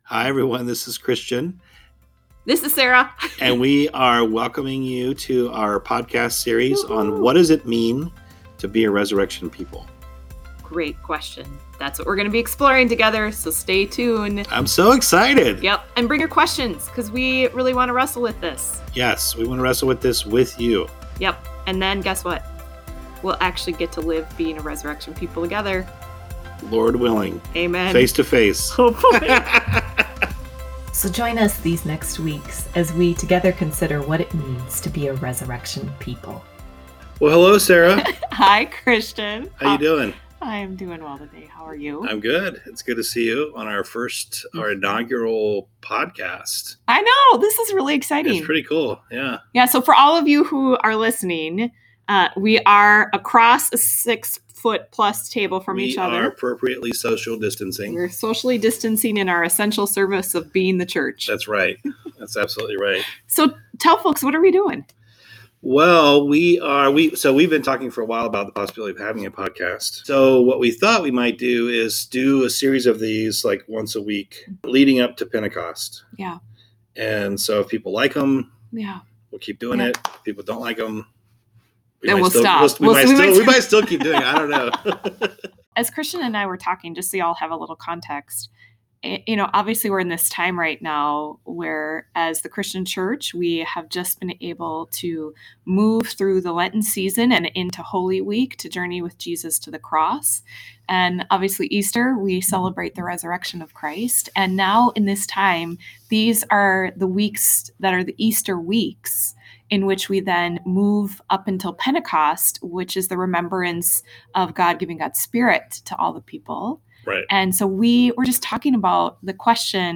audio conversation